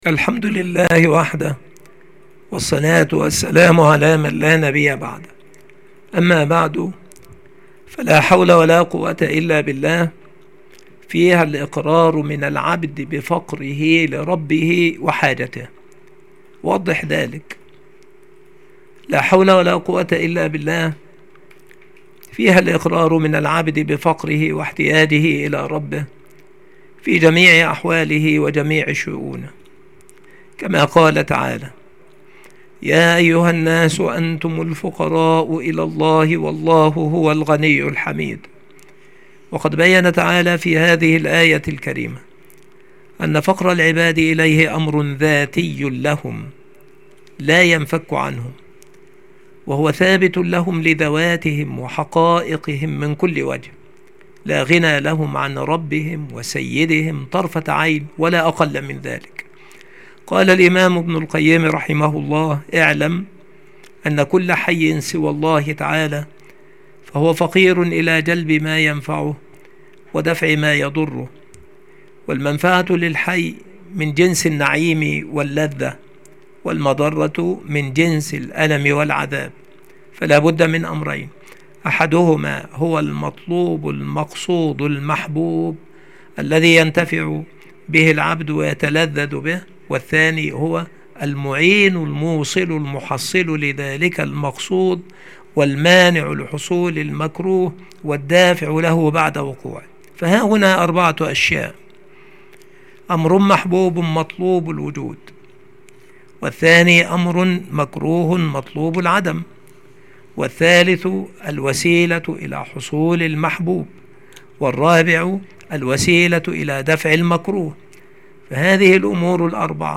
• مكان إلقاء هذه المحاضرة : المكتبة - سبك الأحد - أشمون - محافظة المنوفية - مصر